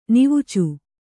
♪ nivucu